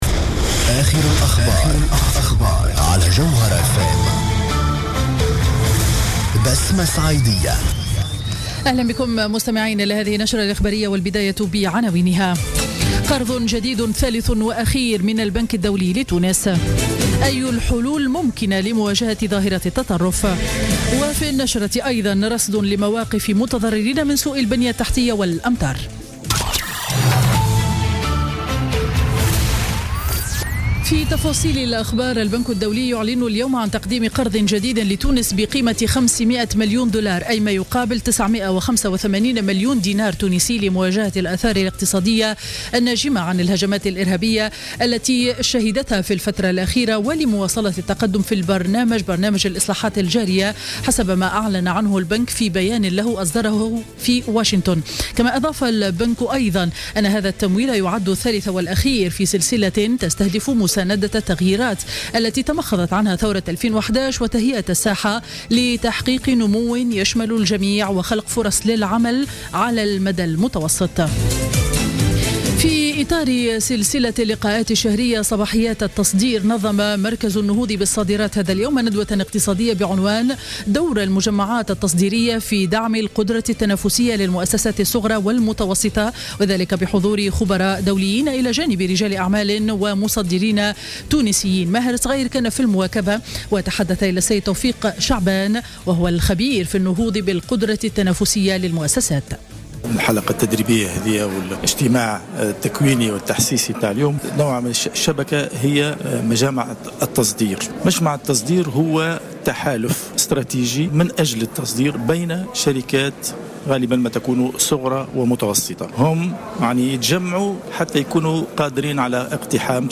نشرة أخبار منتصف النهار ليوم الجمعة 2 أكتوبر 2015